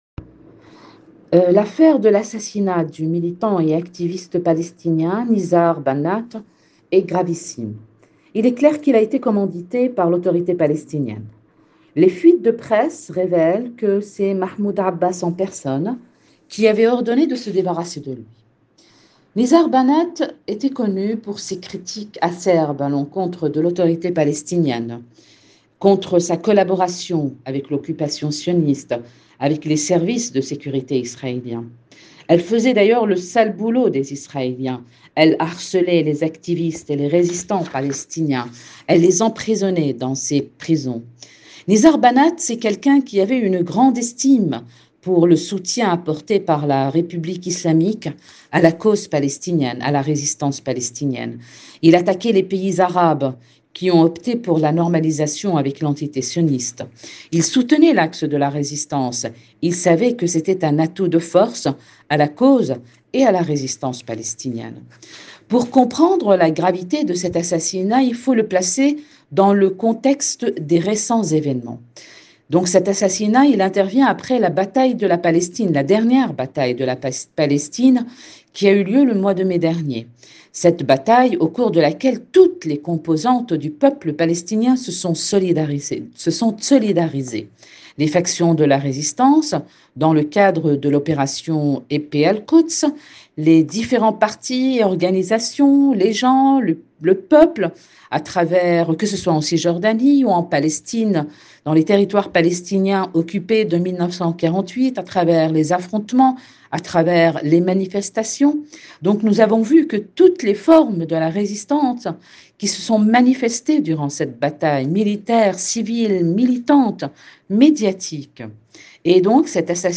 Dans un court entretien